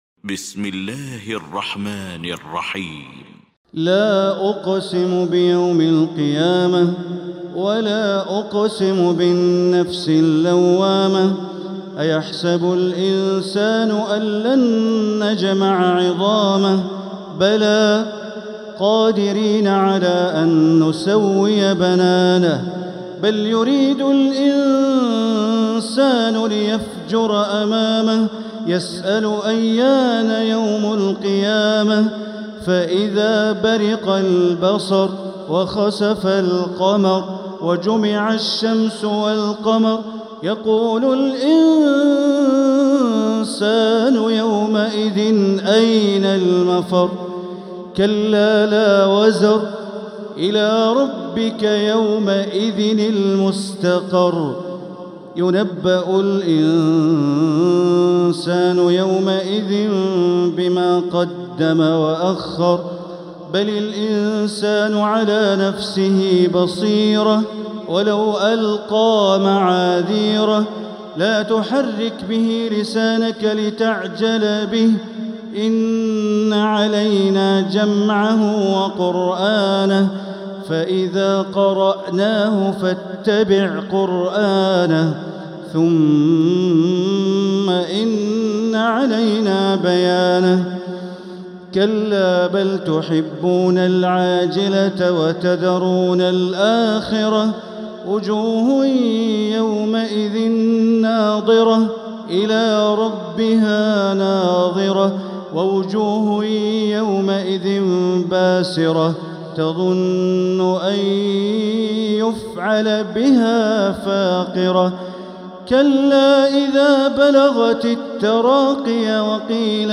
المكان: المسجد الحرام الشيخ: معالي الشيخ أ.د. بندر بليلة معالي الشيخ أ.د. بندر بليلة القيامة